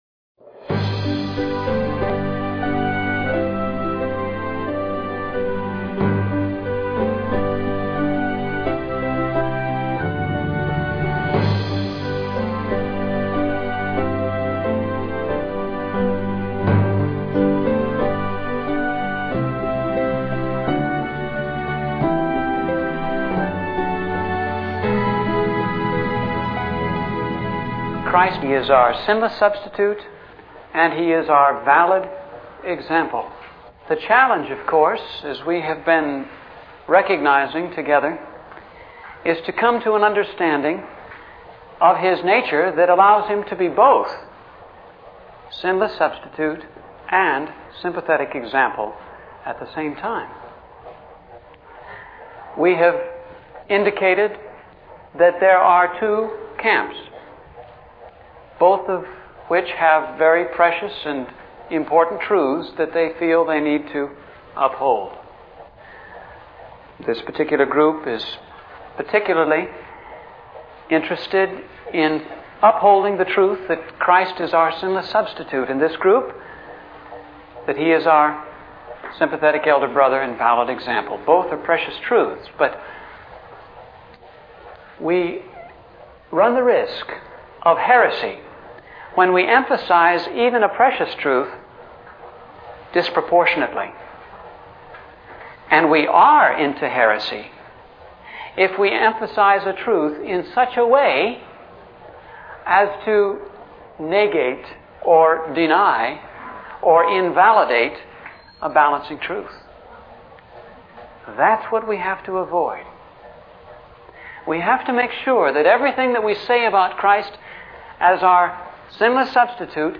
just type CTRL-F (Find function) in your web browser and insert few words you will hear as you start to listen the lecture again.